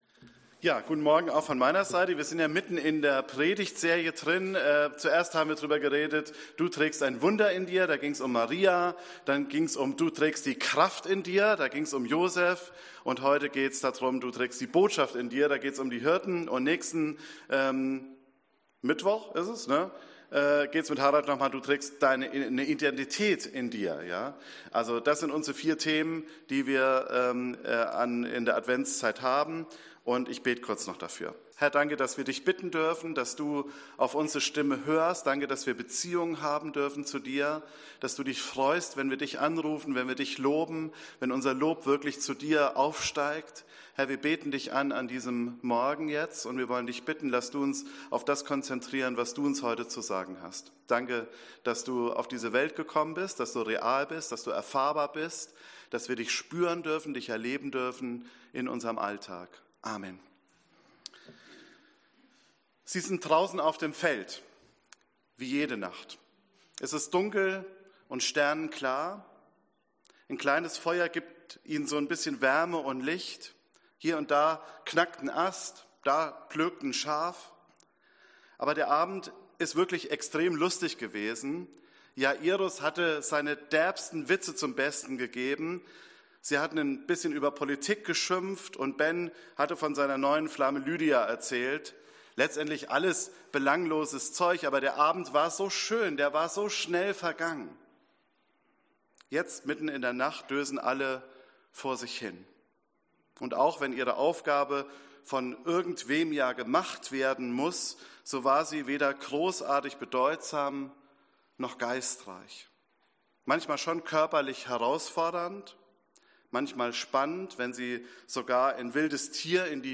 Predigt C1 - Christus zuerst, eins in Christus